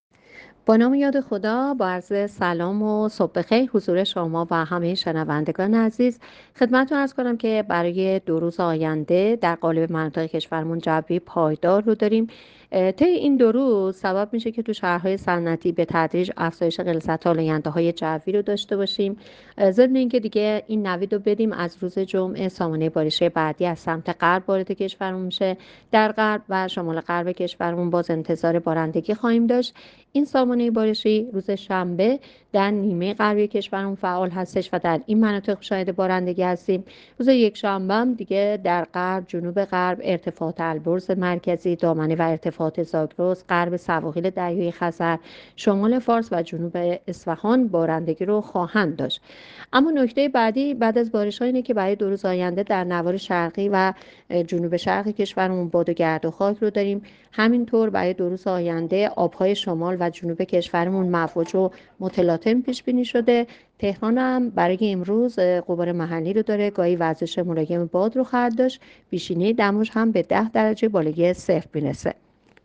گزارش رادیو اینترنتی پایگاه‌ خبری از آخرین وضعیت آب‌وهوای ۳ بهمن؛